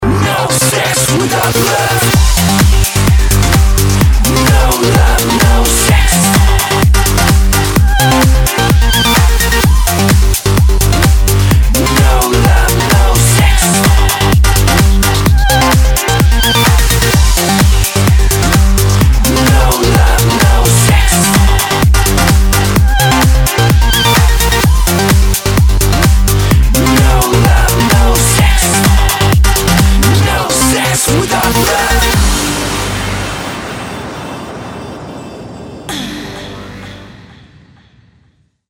• Качество: 192, Stereo
веселые
заводные
качает